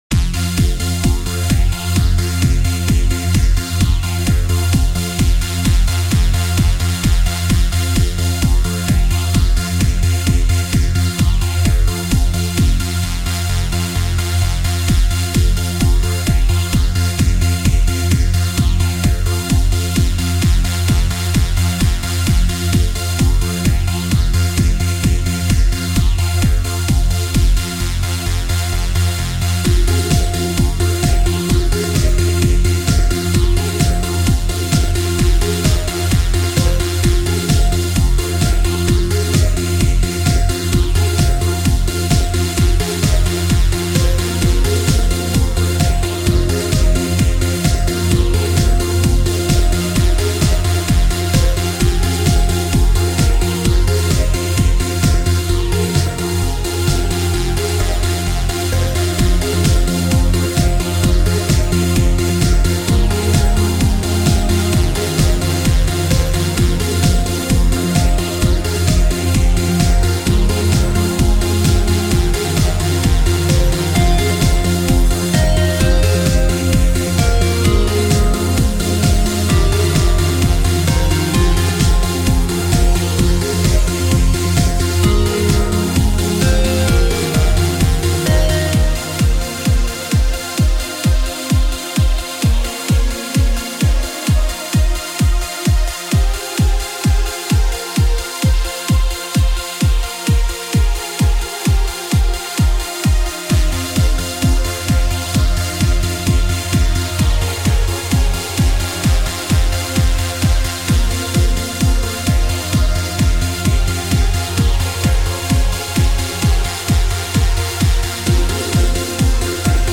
genre:remix